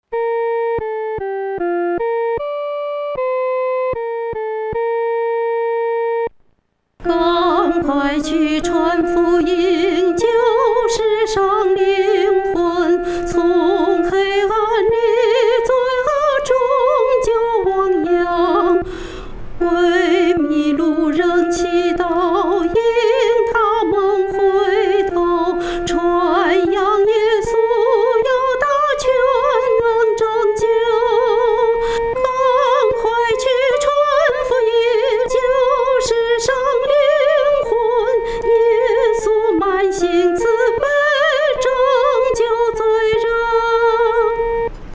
独唱（第一声）